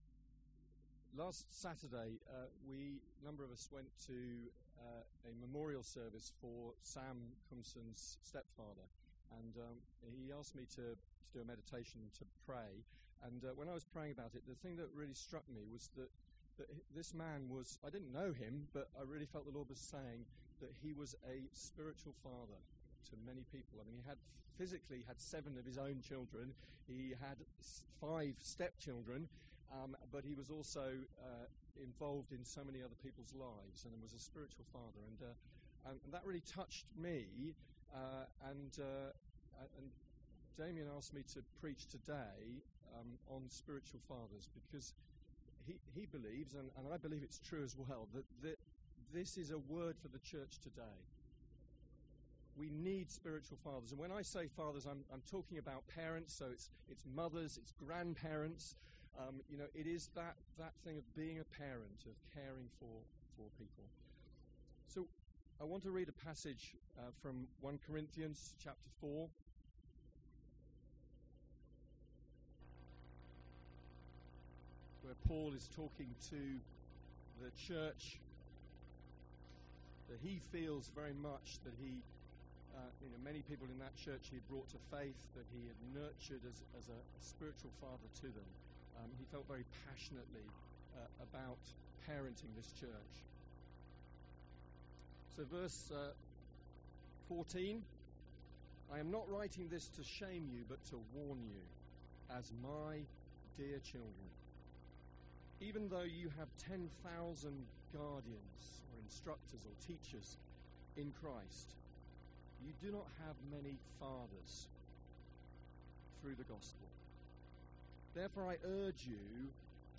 Preaching from 1 Corinthians 4